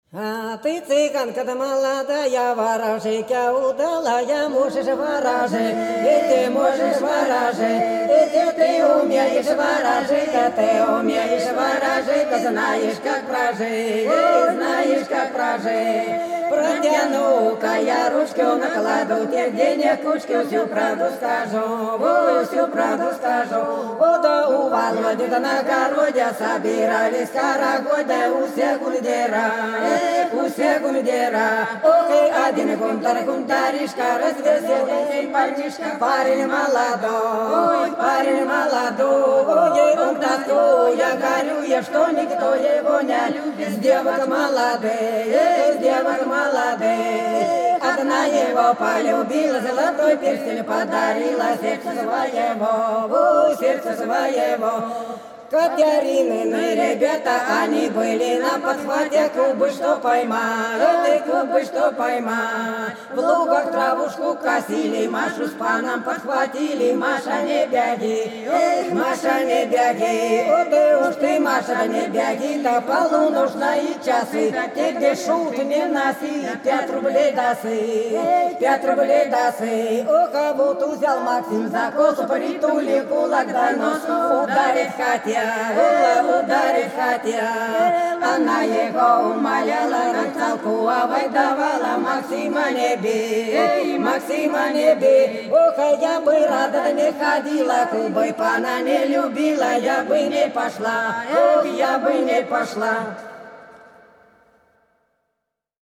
Белгородские поля (Поют народные исполнители села Прудки Красногвардейского района Белгородской области) Ты, цыганка молодая - плясовая